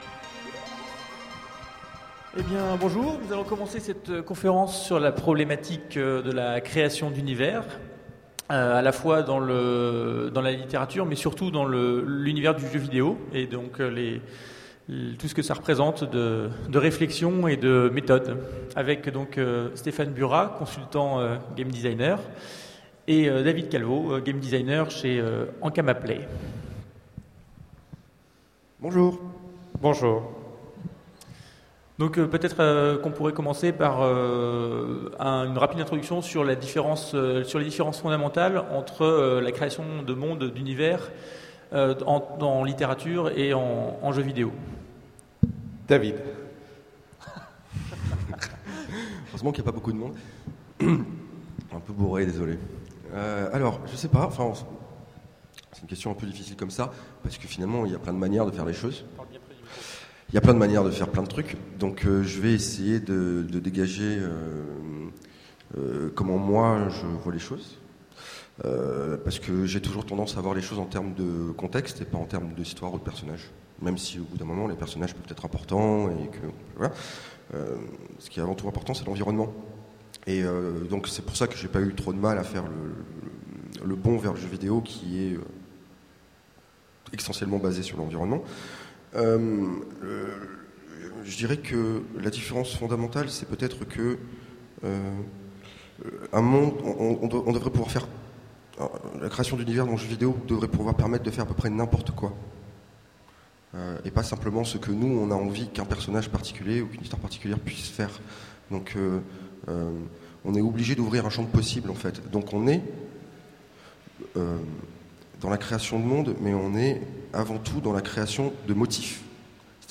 Voici l'enregistrement de la conférence Symboles jouables, la création d'univers interactifs pour le jeu vidéo aux Utopiales 2010.